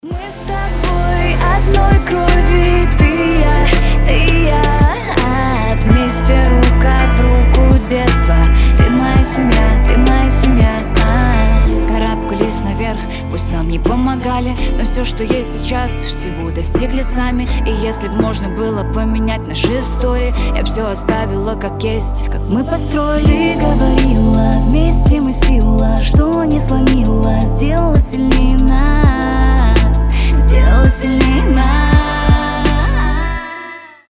Рэп рингтоны
Душевные